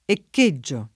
echeggiare v.; echeggio [ek%JJo], ‑gi — fut. echeggerò [ekeJJer0+] — ant. e raro eccheggiare: eccheggio [